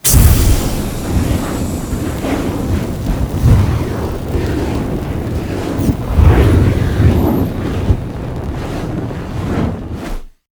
zharka_blowout.ogg